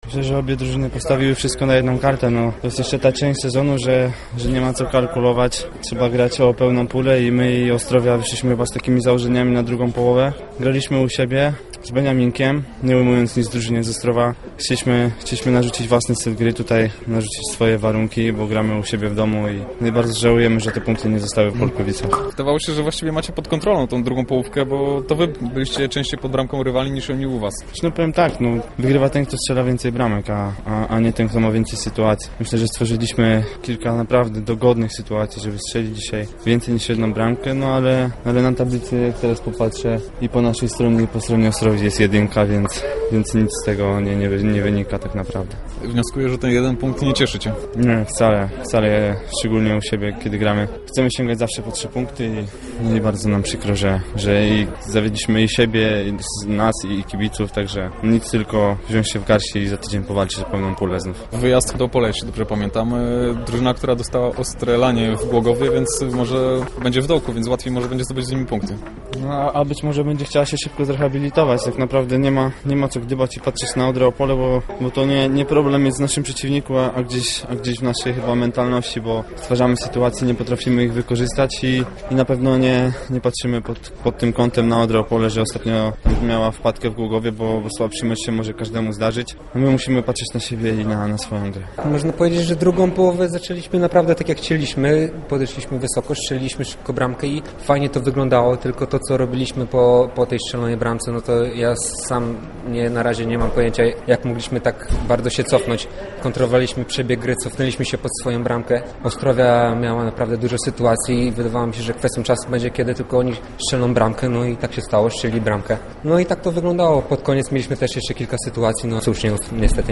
Pomeczowe komentarze